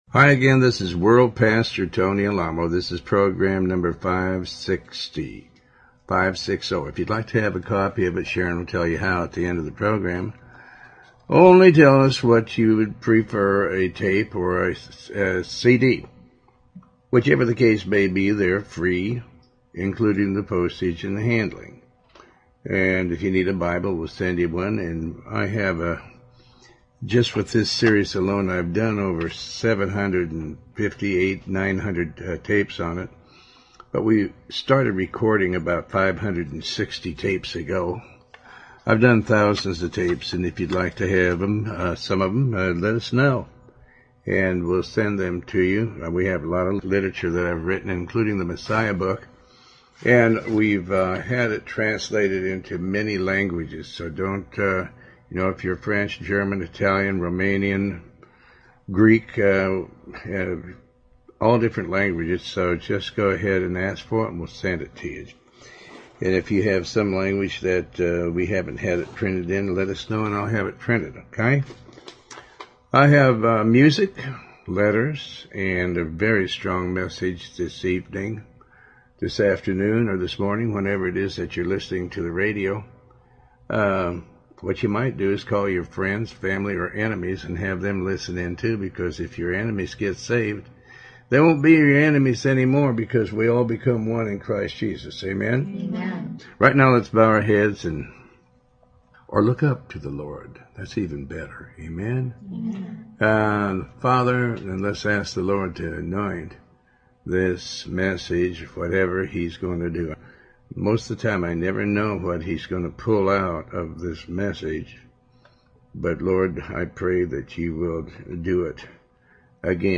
Talk Show Episode, Audio Podcast, Tony Alamo and Endure until the end and never be discouraged.